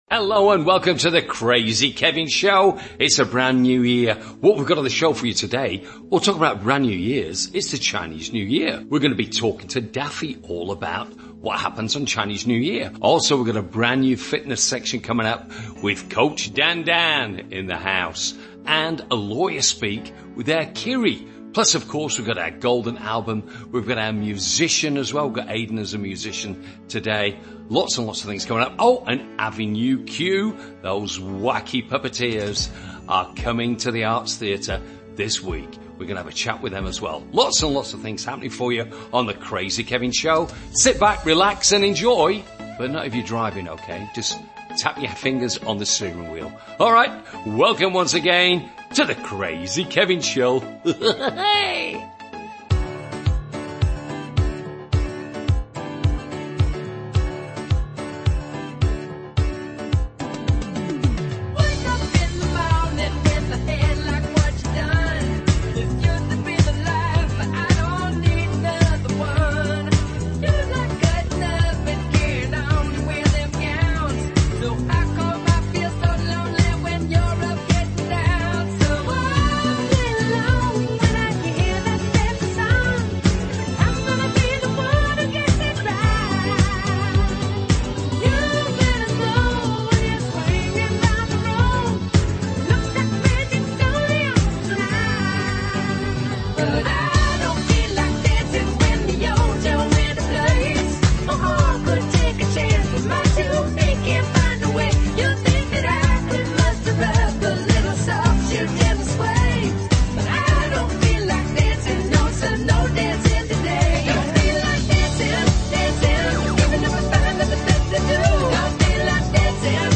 Interview with Actors and Puppets from the "Avenue Q" Show